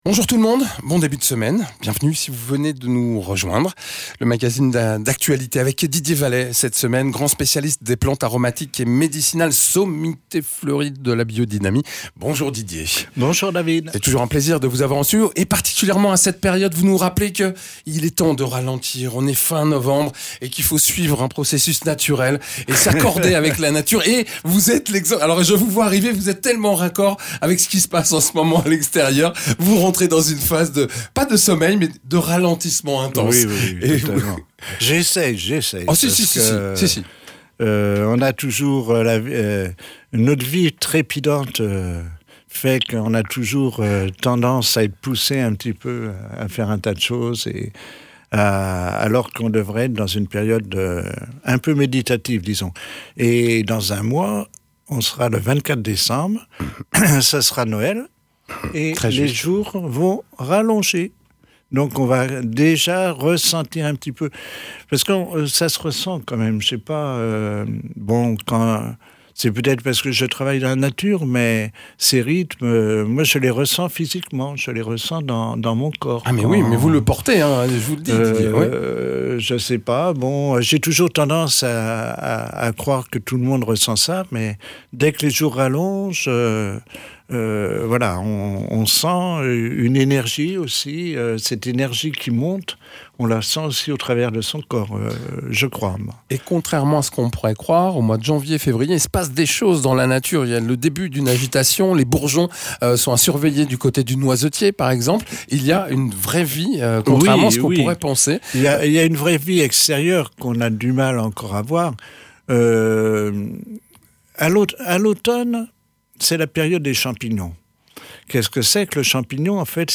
paysan spécialisé dans les plantes aromatiques et médicinales et la biodynamie